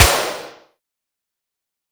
power_ball_explosion.wav